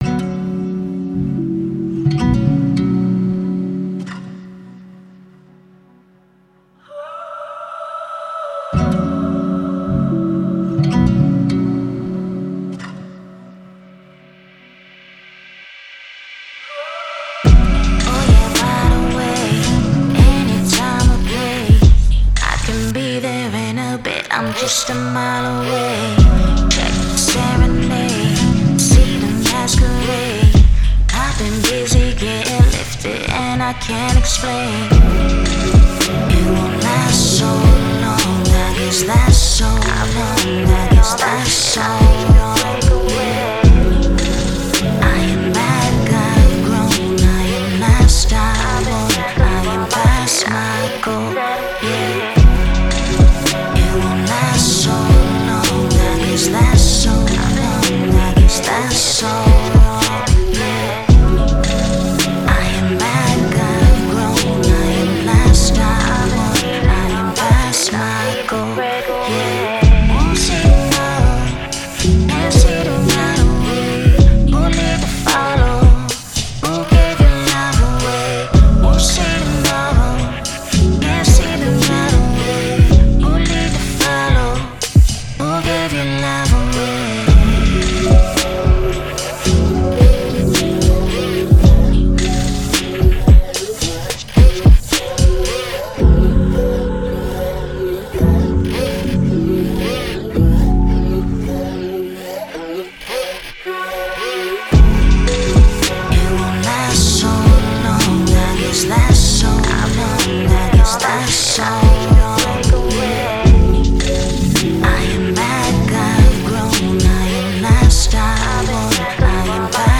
Genre: downtempo.